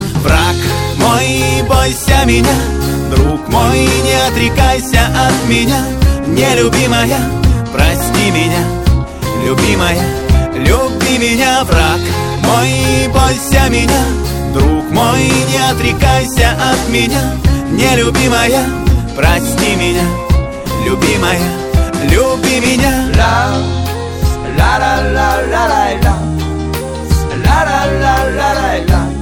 • Качество: 128, Stereo
позитивные
спокойные